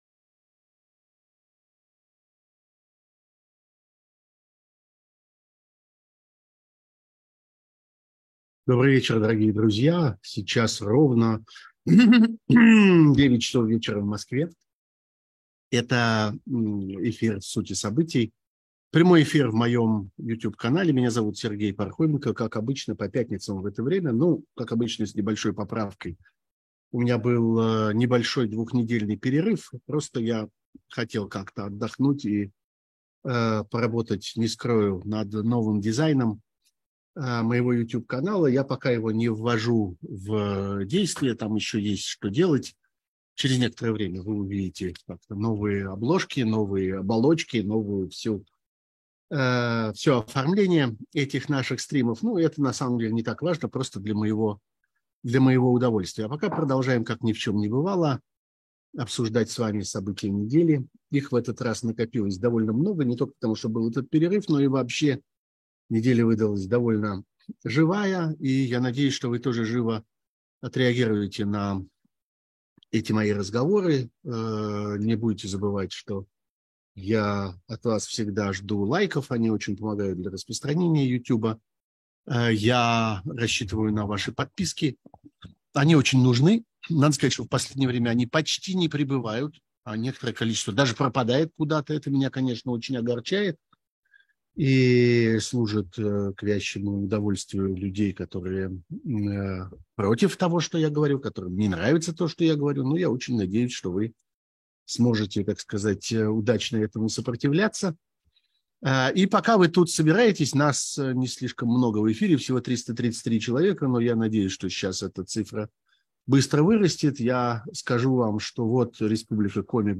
Это эфир «Сути событий», прямой эфир в моем YouTube-канале.